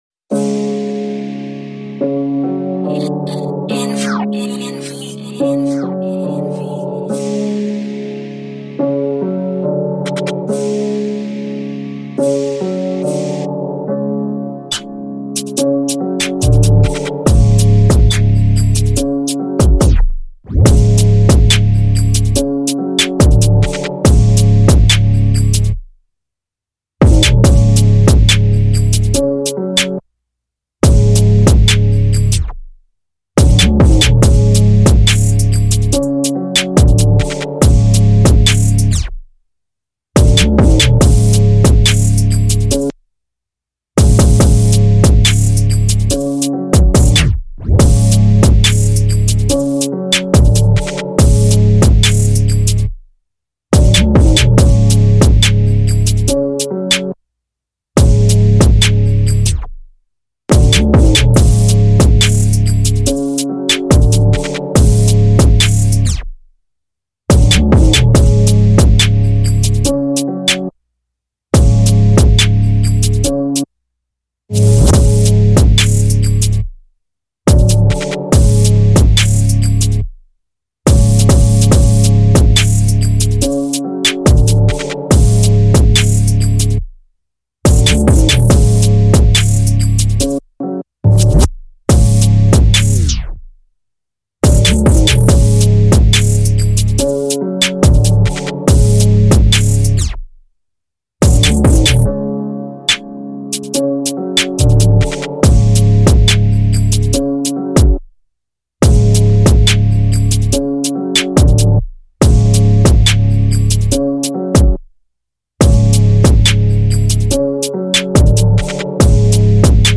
official instrumental
UK Drill Instrumentals